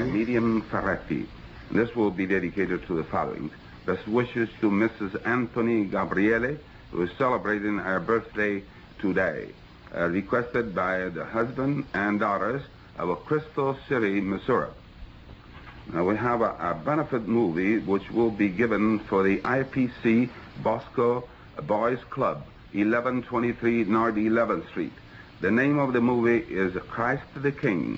WEW Unknown Announcer aircheck · St. Louis Media History Archive
Original Format aircheck